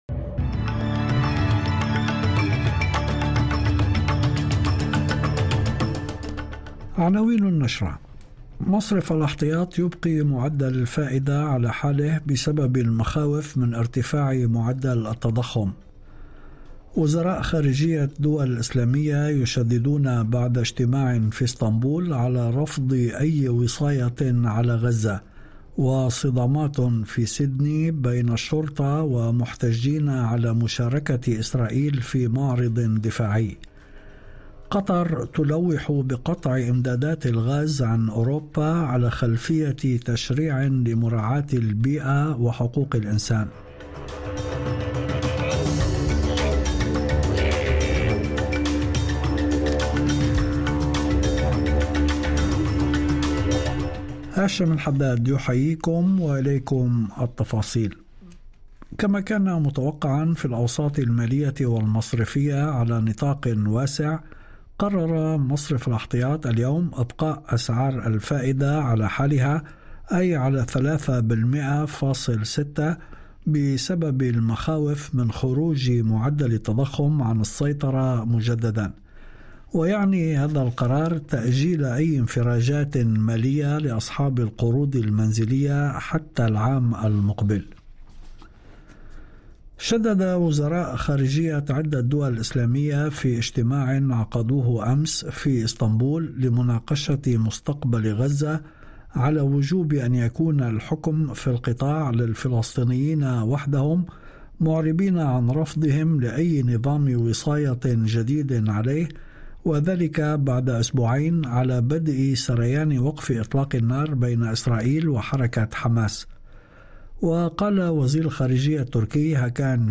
نشرة أخبار المساء 04/11/2025
news-bulletin-4-november-2025.mp3